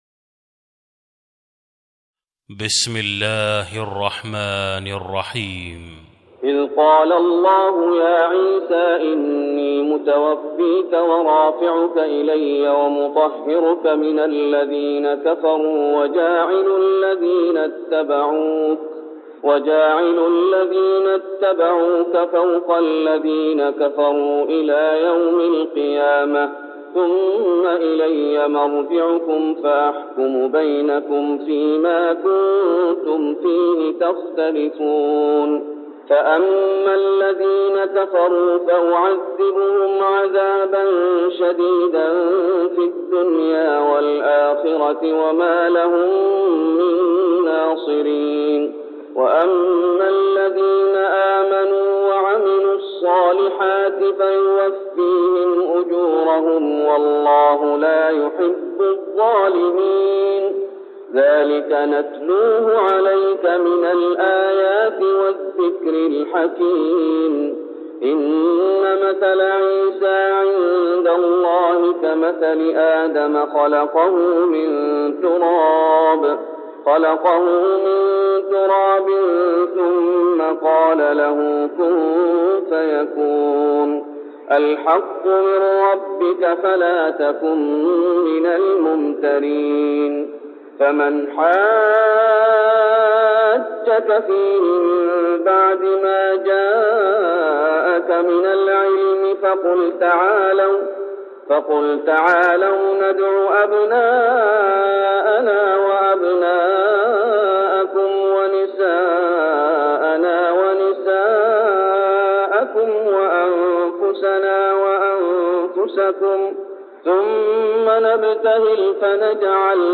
تهجد رمضان 1410هـ من سورة آل عمران (55-92) Tahajjud Ramadan 1410H from Surah Aal-i-Imraan > تراويح الشيخ محمد أيوب بالنبوي عام 1410 🕌 > التراويح - تلاوات الحرمين